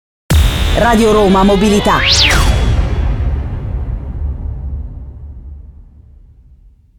SWEEPER-1-F-RRM.mp3